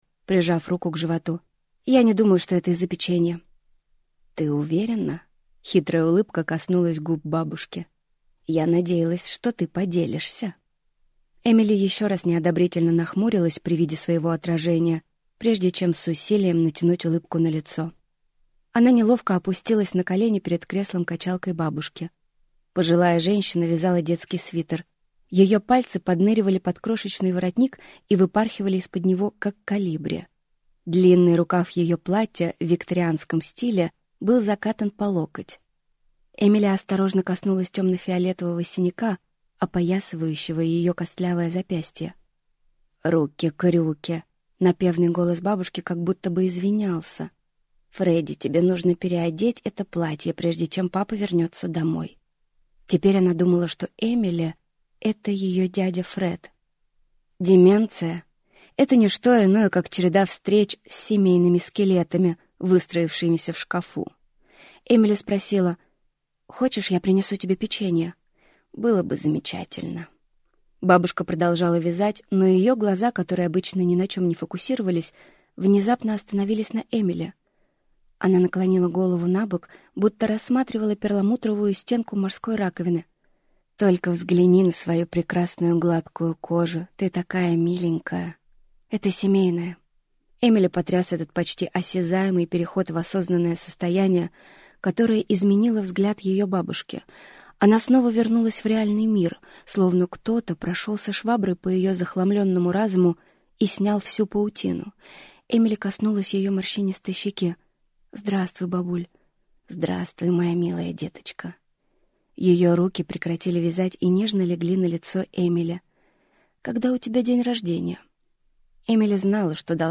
Аудиокнига Забытая девушка | Библиотека аудиокниг